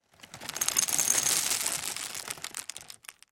Насыпаем чуть нут